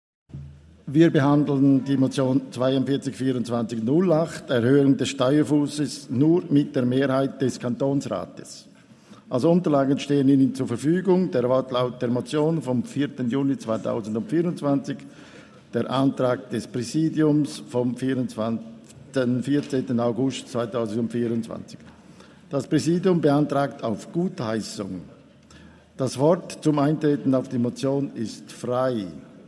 16.9.2024Wortmeldung
Session des Kantonsrates vom 16. bis 18. September 2024, Herbstsession